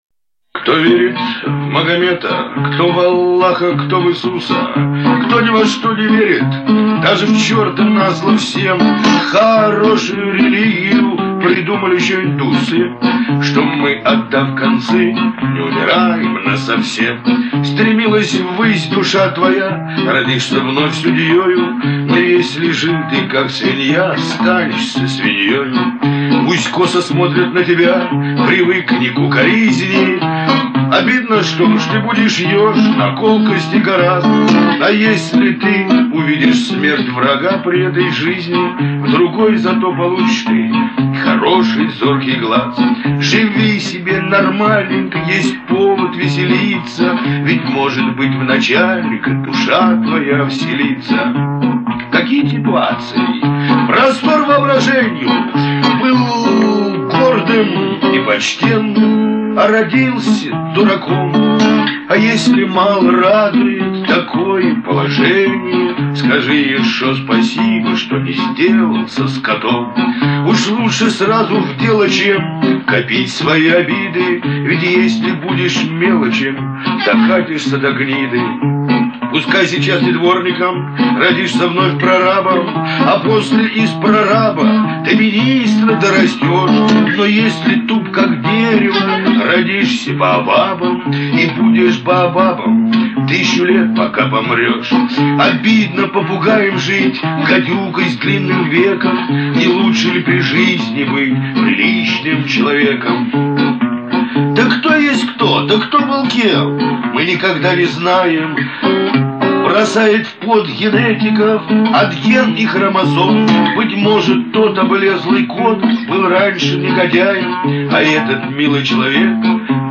Бардовские песни